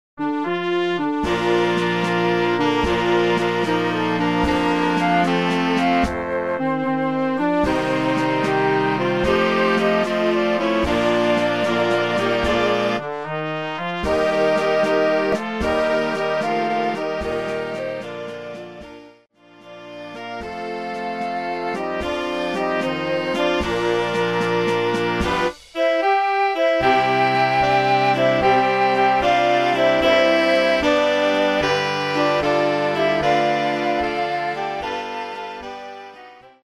kościelne